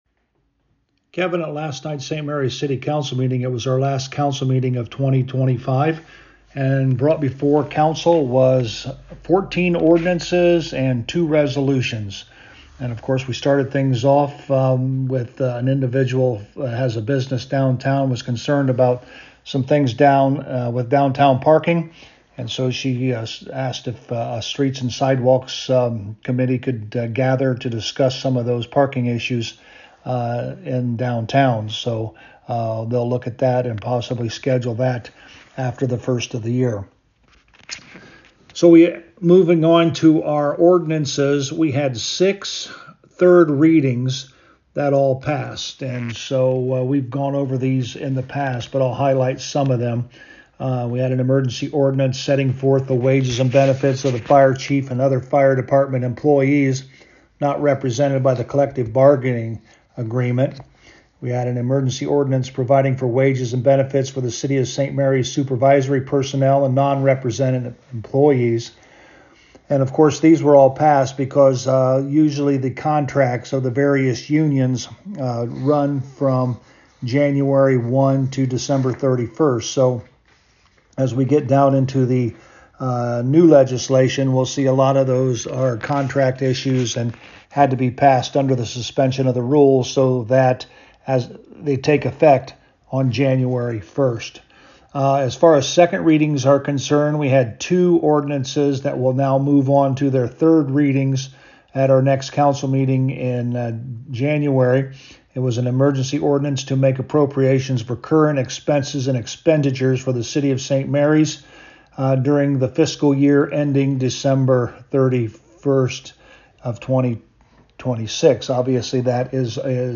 To hear Mayor Hurlburt: